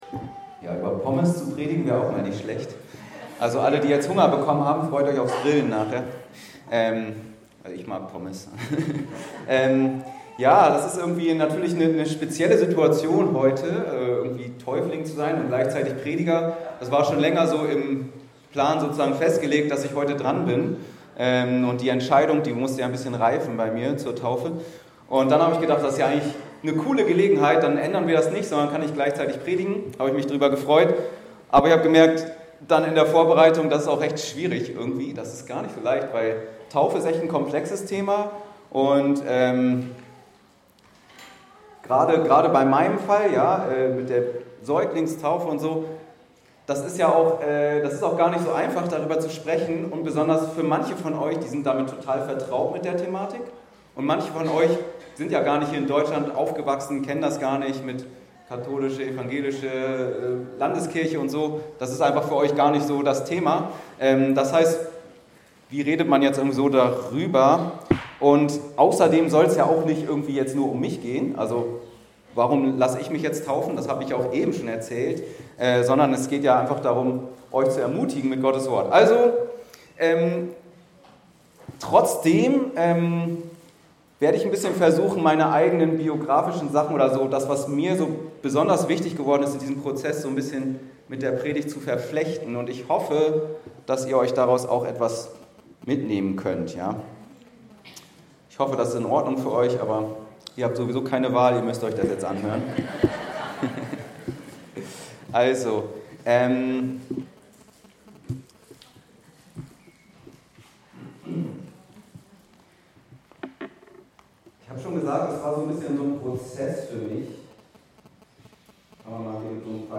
Was hindert’s, mich taufen zu lassen ~ Anskar-Kirche Hamburg- Predigten Podcast